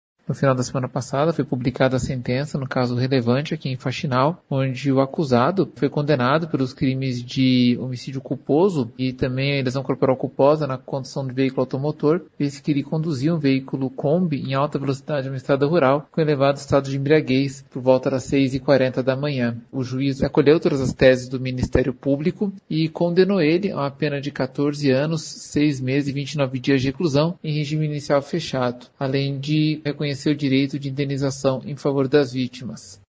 Ouça o que diz sobre este caso o promotor de Justiça Gabriel Thomaz da Silva.